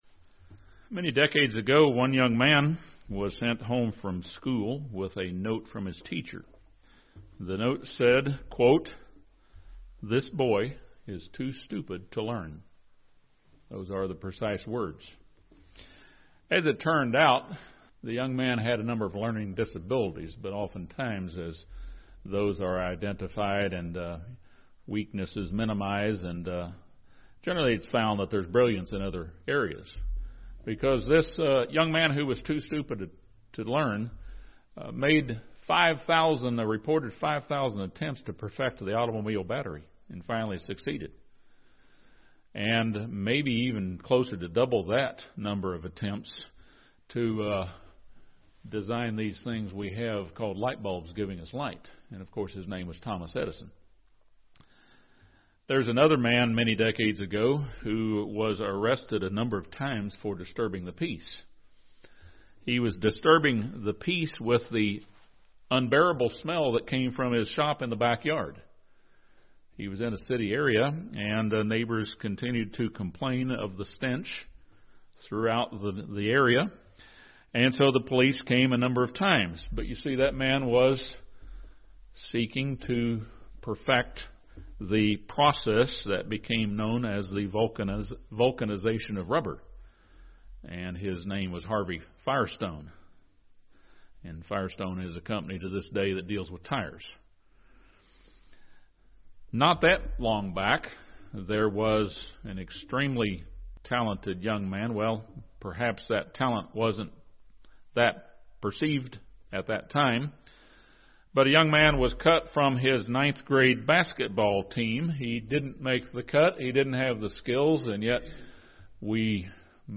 This sermon looks at the examples of Lot, Delilah, and Solomon. Three lessons are drawn from each one's familiar failures.